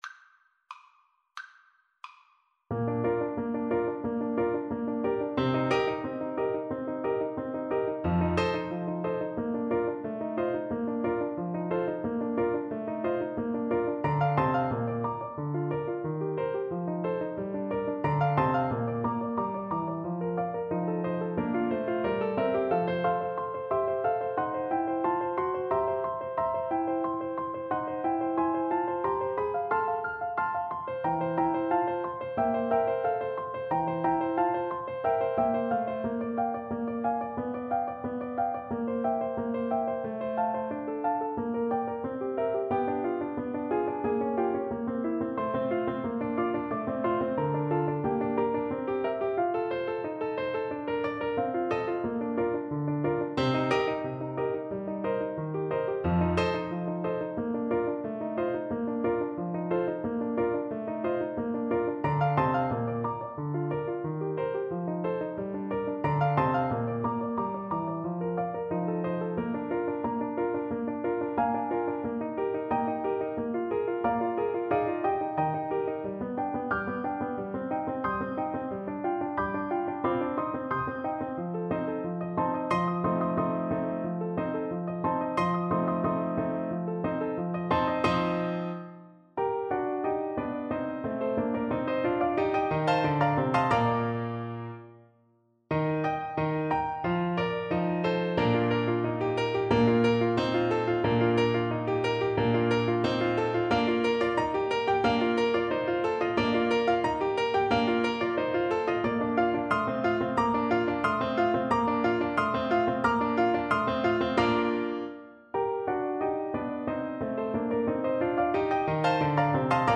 Alto Saxophone
2/2 (View more 2/2 Music)
Bb major (Sounding Pitch) G major (Alto Saxophone in Eb) (View more Bb major Music for Saxophone )
Allegro vivace =90 (View more music marked Allegro)
Classical (View more Classical Saxophone Music)